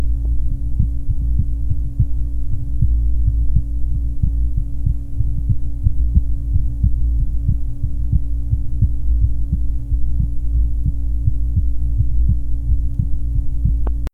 Date 1970 Type Systolic and Diastolic Abnormality Ventricular Septal Defect 11 year old small muscular VSD good third sound. Channel 3-4 [c-d?] difficult to get good.